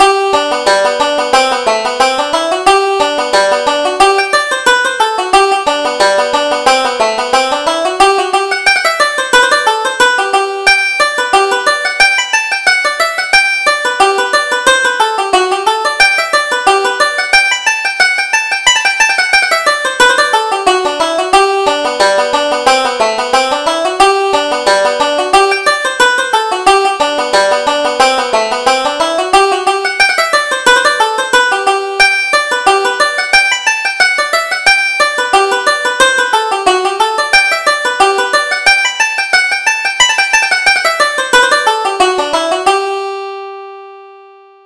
Reel: McFadden's Mishap